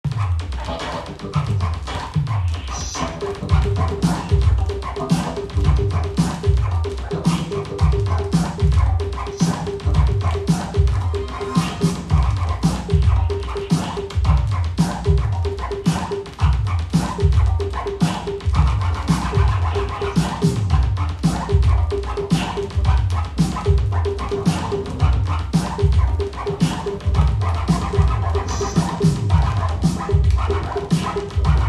LP - Coletânea, Nacional
Gênero: Funk, Hip Hop, Soul
com a reprodução original do disco anunciado: